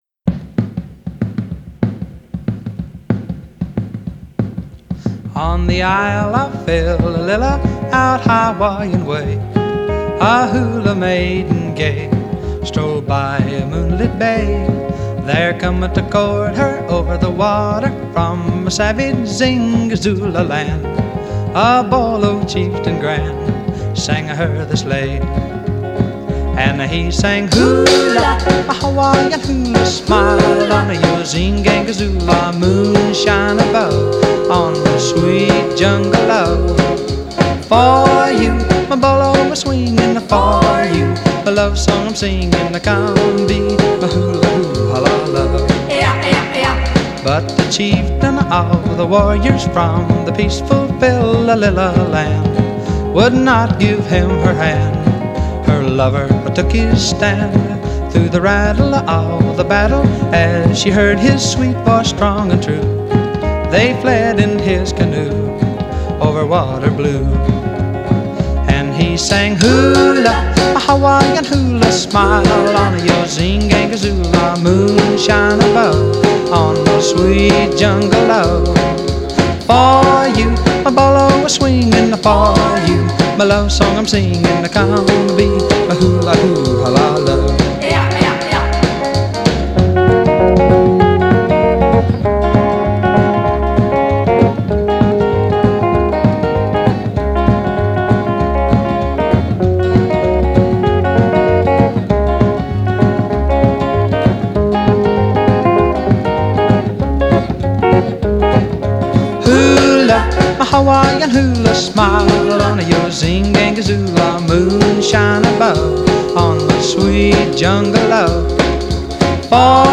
Эти студийные варианты я слышал.